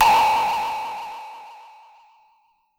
pbs - tm88 [ Sfx ].wav